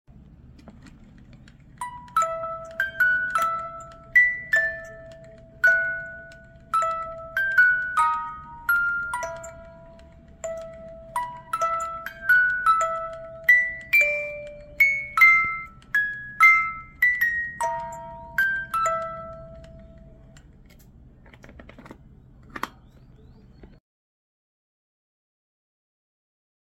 Cajita musical de CUERDA